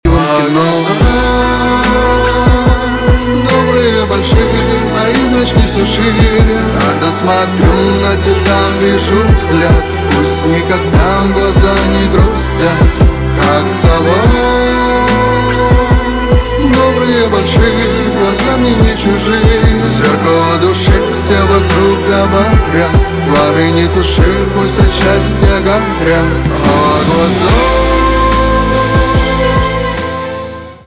поп
чувственные , скрипка